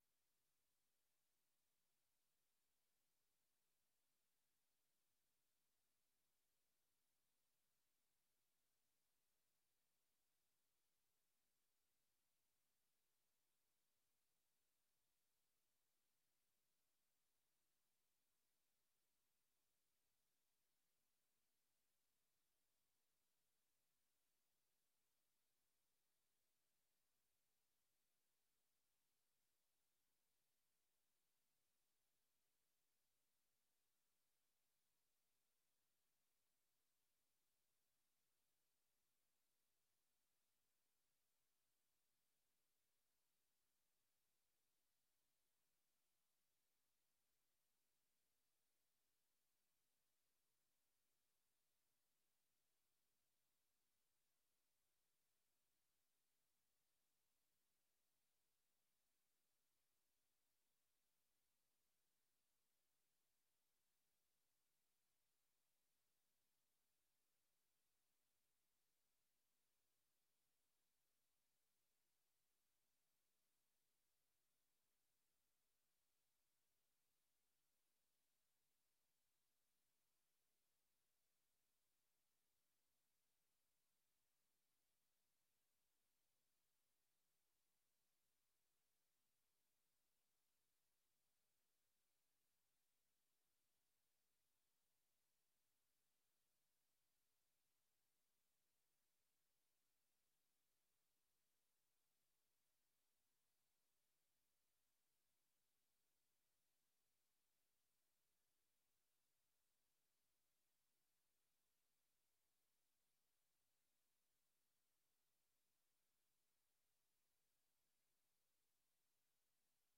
Download de volledige audio van deze vergadering
Locatie: Voorrondezaal Lingewaal